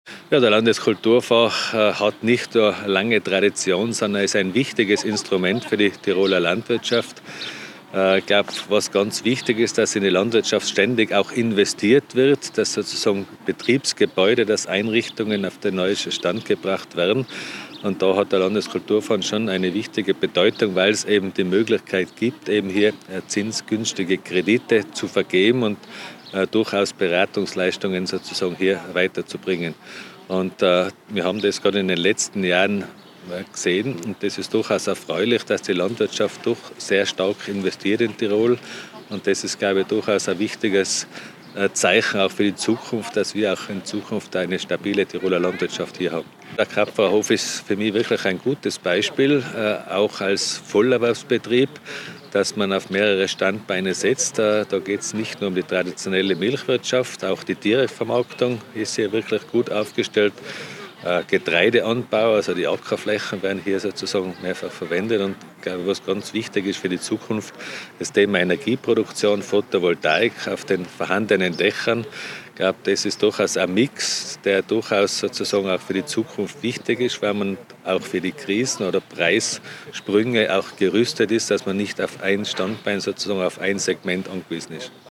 OTon_LHStv_Geisler.mp3